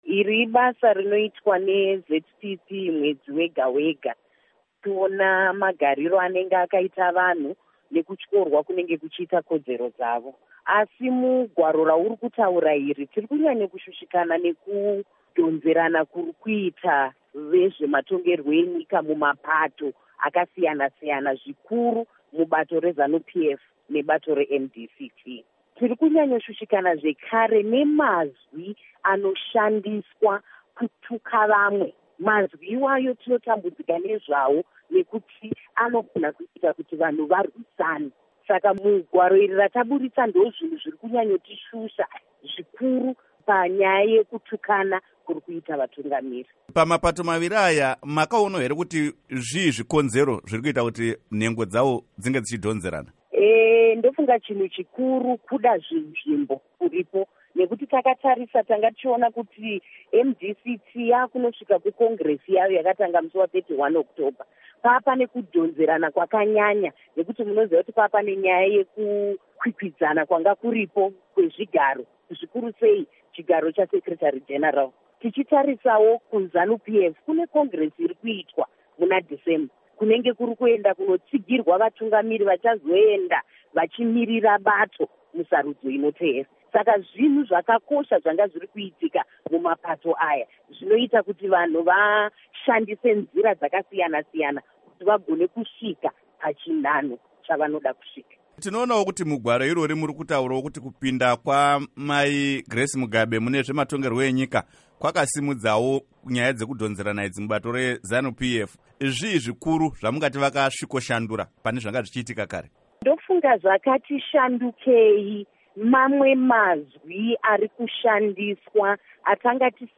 Hurukuro naMuzvare Jestina Mukoko